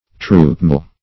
Search Result for " troopmeal" : The Collaborative International Dictionary of English v.0.48: Troopmeal \Troop"meal`\, adv.